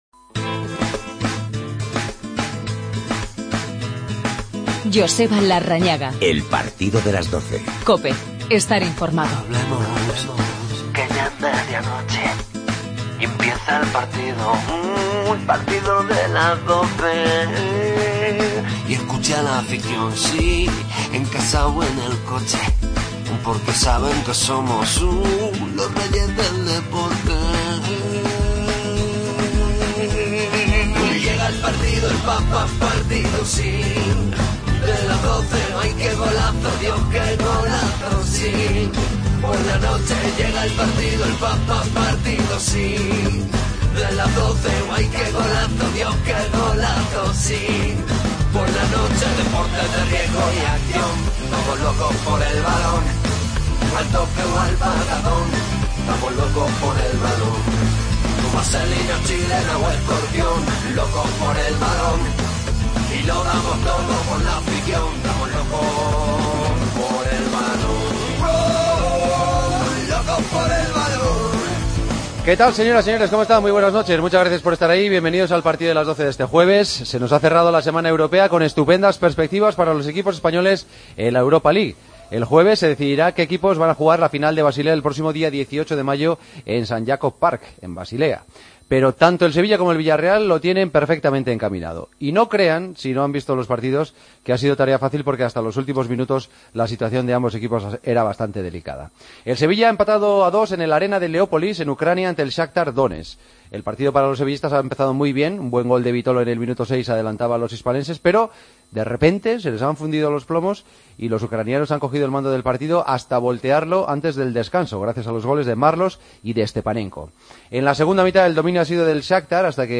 El Sevilla empató a dos en la ida de semifinales de la Europa League; el Villarreal ganó 1-0 al Liverpool. Entrevistas a Vitolo y Denis Suárez.